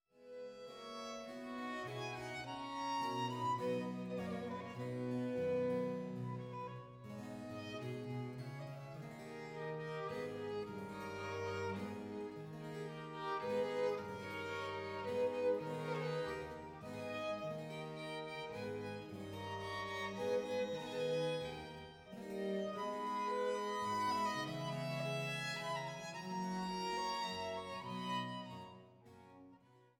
Traverso
Violino
Violoncello
Cembalo